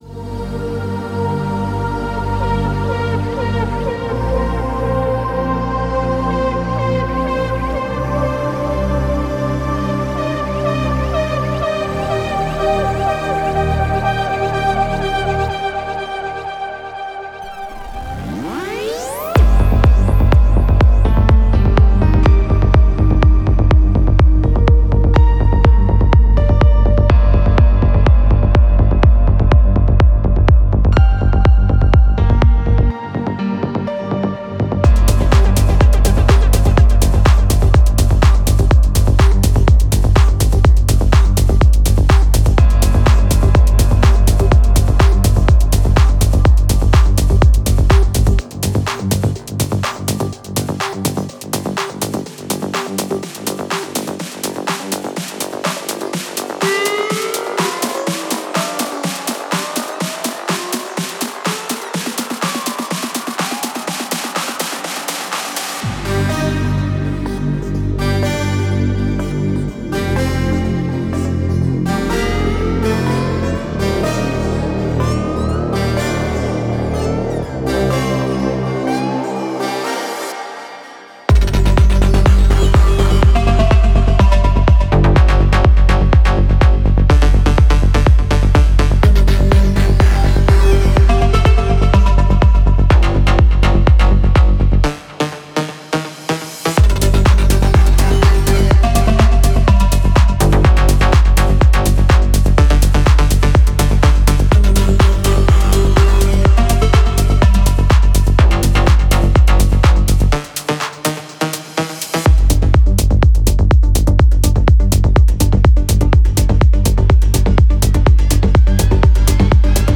sounds for melodic techno music production
MP3 DEMO